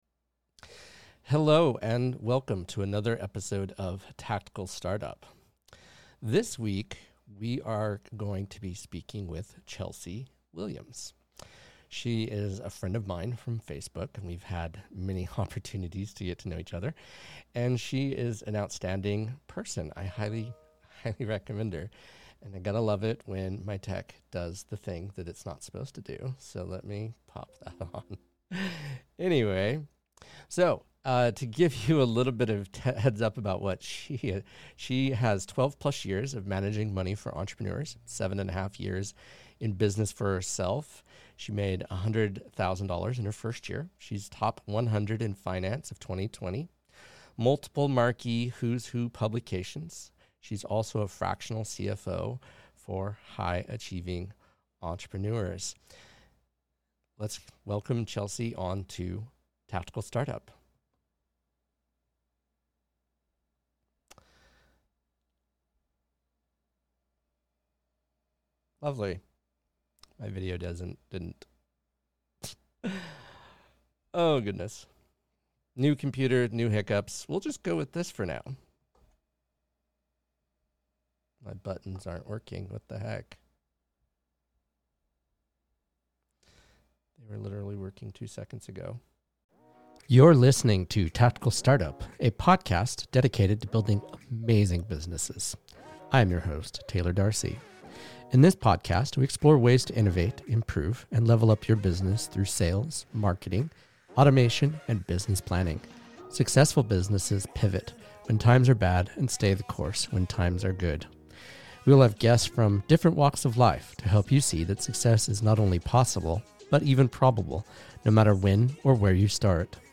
Shure SM7B Microphone
Rode Rodecaster Pro